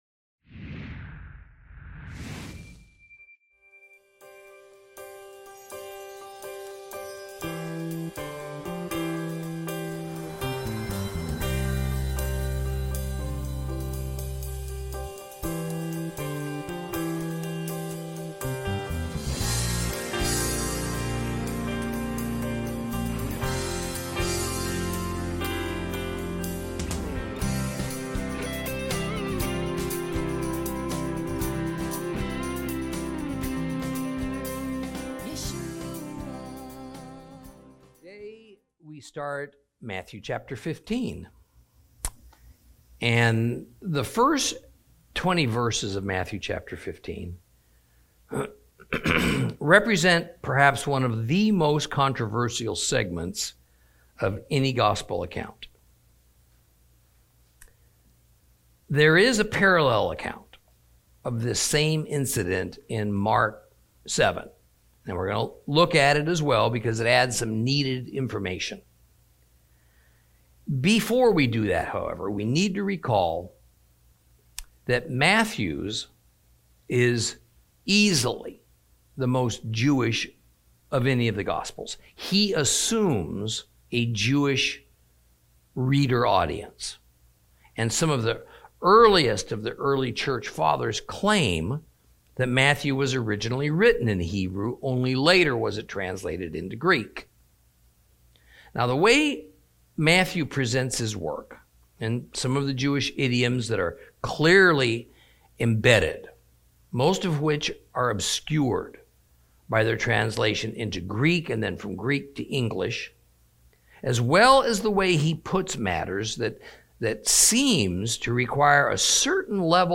Lesson 53 Ch15 - Torah Class